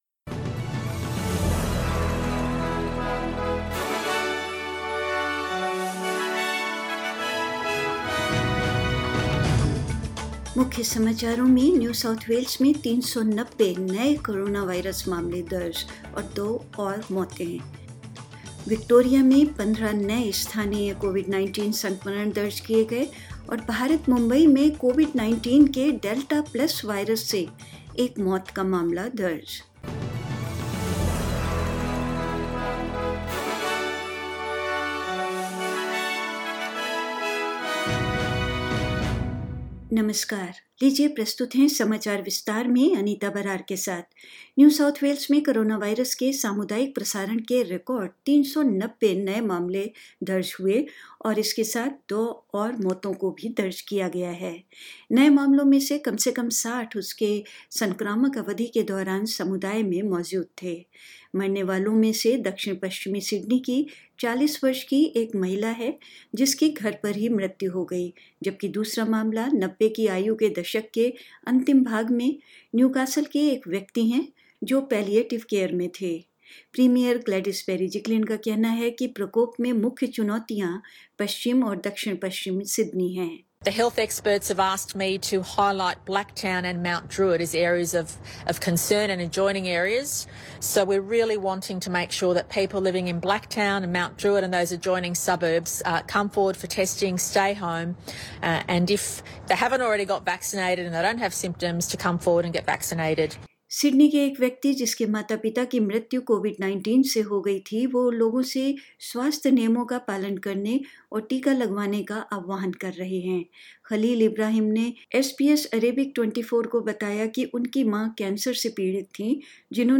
In this latest SBS Hindi News bulletin: New South Wales records 390 new coronavirus cases and two more deaths; Fifteen new local COVID-19 infections recorded in Victoria; Federal government is working to extract remaining Australians in Afghanistan as the Taliban offensive accelerates and more